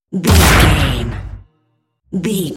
Dramatic hit drum metal
Sound Effects
Atonal
heavy
intense
dark
aggressive